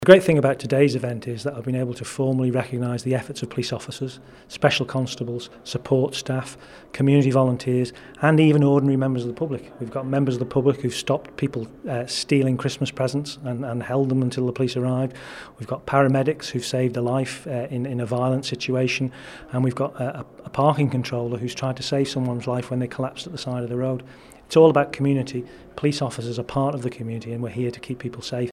Chief Constable Gary Roberts says the event is a great opportunity to celebrate the Island's fantastic community: